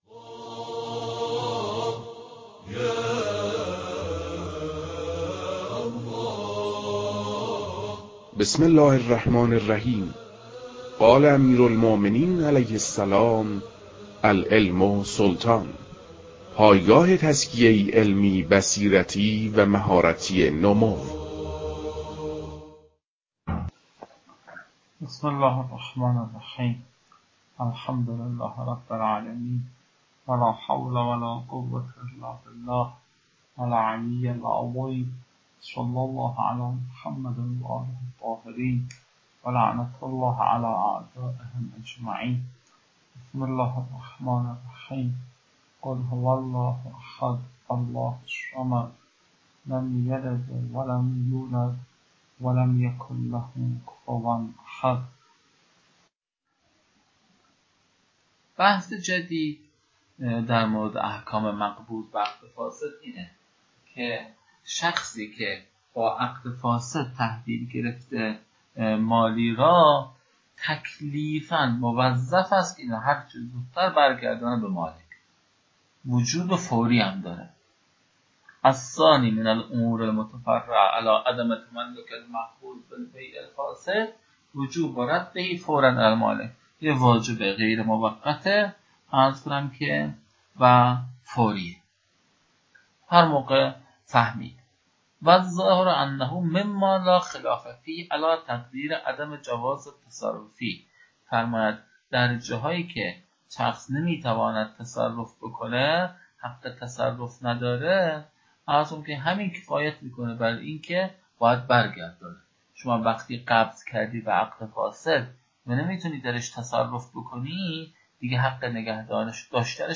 فایل های مربوط به تدریس مباحث تنبیهات معاطات از كتاب المكاسب متعلق به شیخ اعظم انصاری رحمه الله